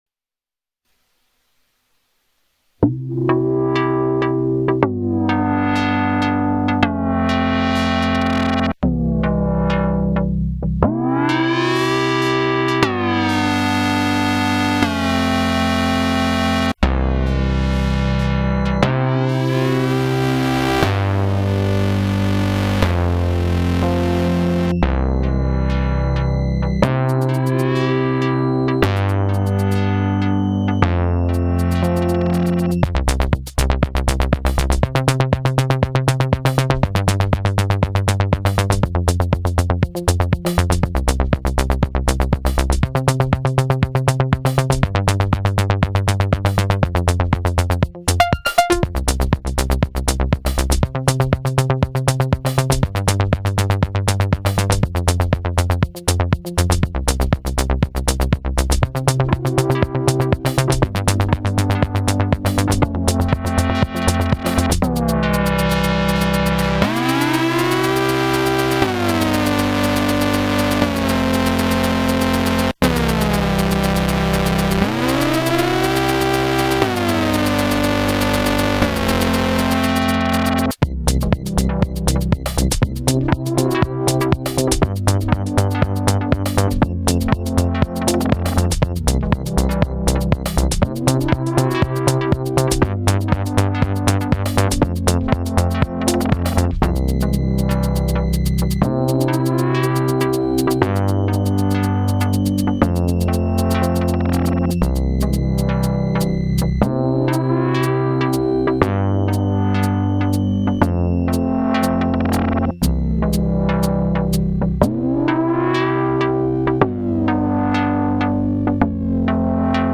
Well, actually, it was my third take - I’m using the Squarp Pyramid to loop a patch + cc messages on my Bass Station 2, then I play a simple loop on the Volca FM that later gets thrown through the arpeggiator. Drums are coming from a SP404 sequenced by the Pyramid. Recorded directly into the 404.
Levels are a bit fucky and my timing was off in a few places, but overall I’m proud of it. I really like what happened at ~1:18 with the transition out of the drone-y breakdown.